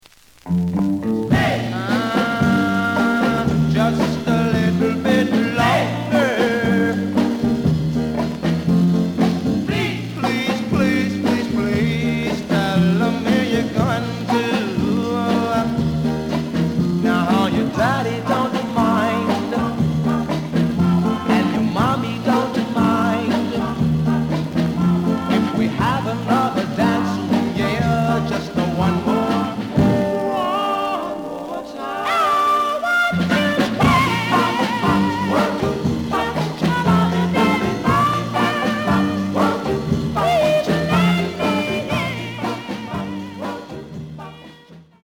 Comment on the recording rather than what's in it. The listen sample is recorded from the actual item.